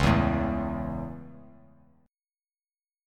Dbm#5 Chord
Dbm#5 chord